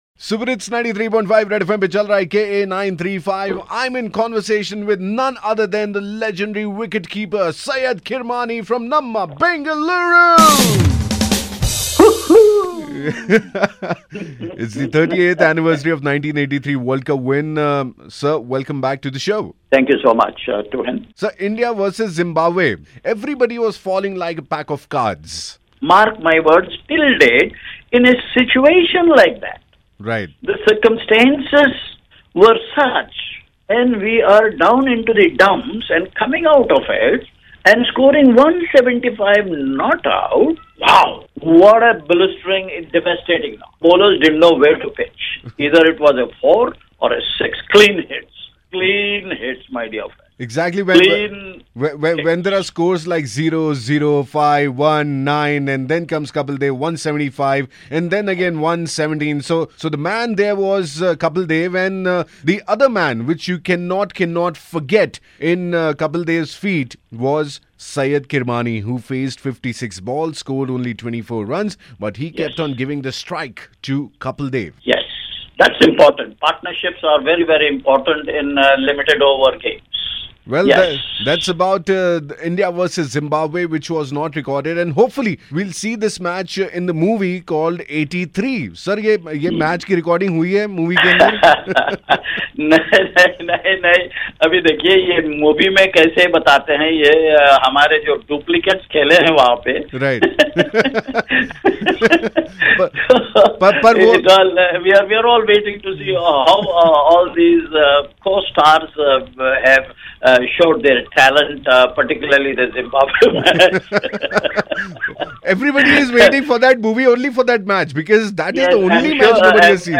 Exclusive Interview with Syed Kirmani